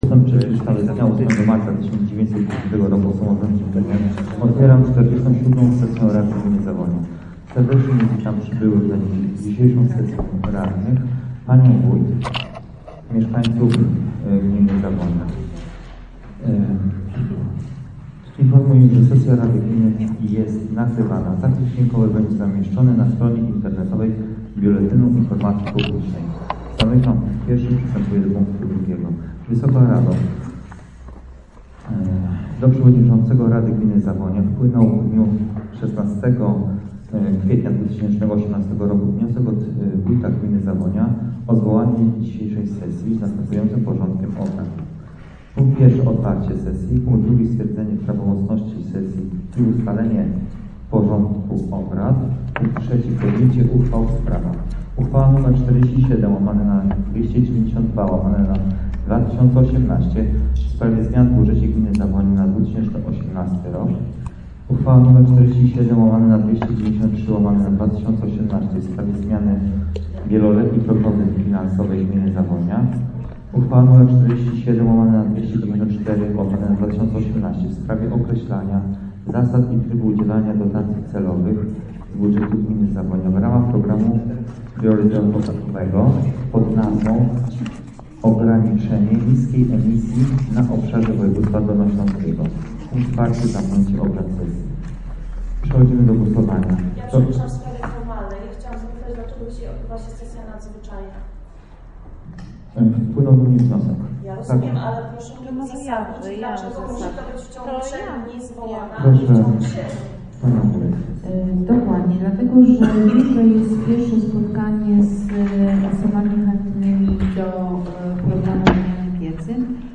Potokoły z sesji Rady Gminy Zawonia w formie nagrań dźwiękowych: